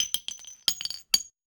weapon_ammo_drop_20.wav